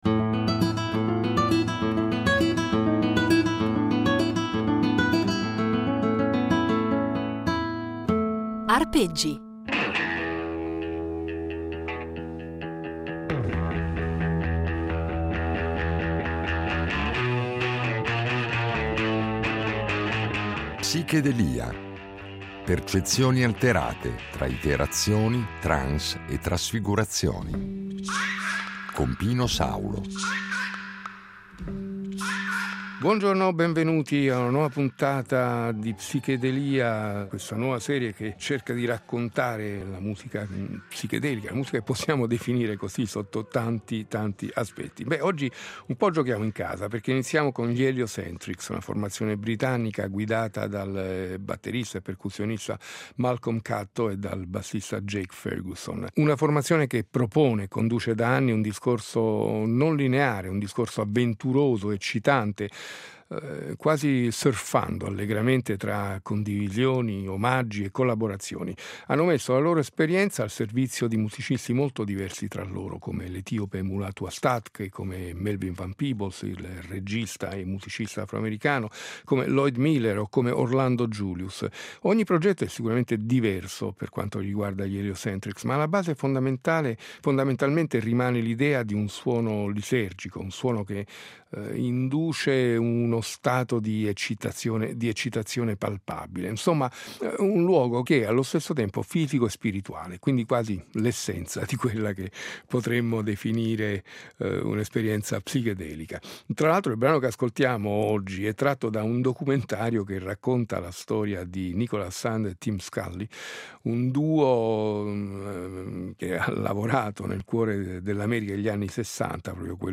Termine volutamente ambiguo, che va a indicare un insieme di musiche nate intorno alla metà degli anni ‘60 aventi in comune un riferimento alle droghe allucinogene, ma nelle quali la musica incorpora elementi provenienti da altre culture musicali - dall’oriente soprattutto, con l’utilizzo di strumenti come il sitar o le tabla - effetti sonori, tecniche di registrazione che pongono in qualche modo elevano lo studio di registrazione al rango di uno strumento al pari degli altri, con l’uso massiccio di loop, nastri al contrario, riverbero, e ancora elementi del jazz modale e più sperimentale, droni, il tutto condito da luci stroboscopiche e testi surreali o con riferimenti più o meno velati a esperienze allucinatorie.